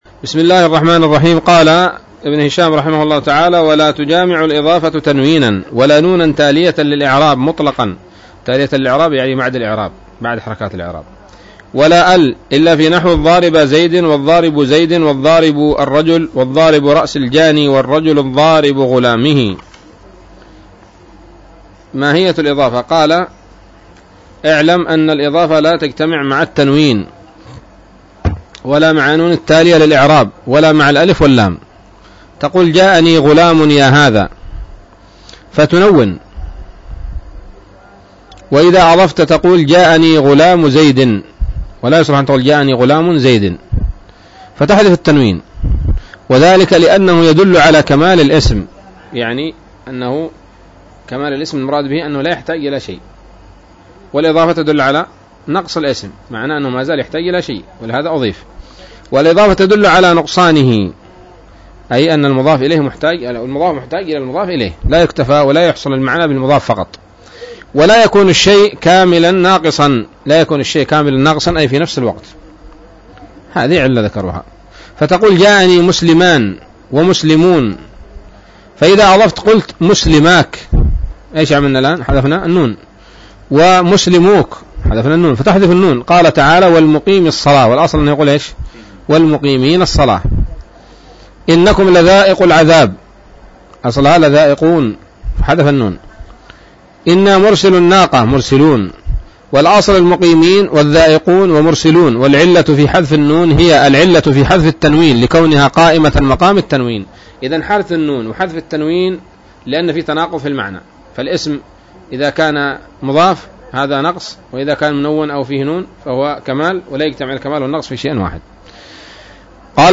الدرس الرابع بعد المائة من شرح قطر الندى وبل الصدى